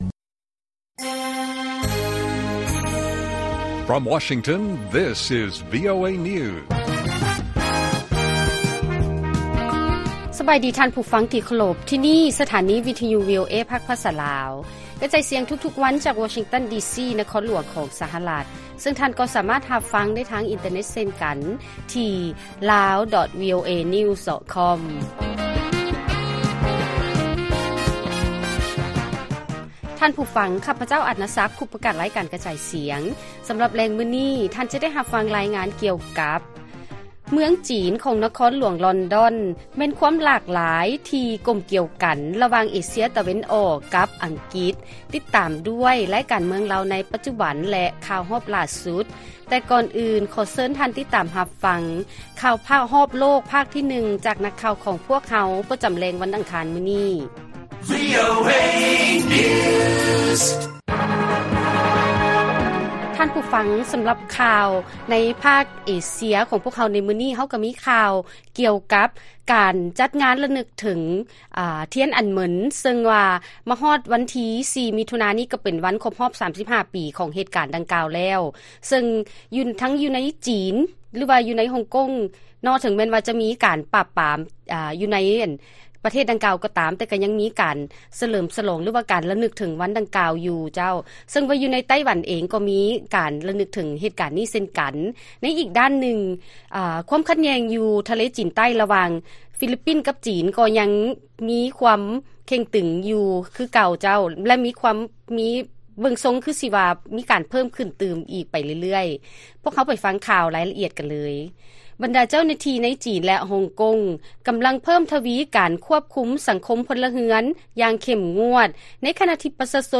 ລາຍການກະຈາຍສຽງຂອງວີໂອເອລາວ: ຊຸມຊົນຊາວອົບພະຍົບ ຈັດງານລະນຶກ ທຽນອັນເໝິນ ເຖິງແມ່ນວ່າຈະມີການປາບປາມຢູ່ໃນຮົງກົງ ແລະ ຈີນ ກໍຕາມ